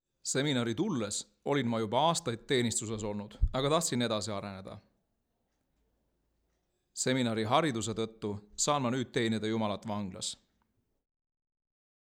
Есть аудиофайл с плавающим темпом.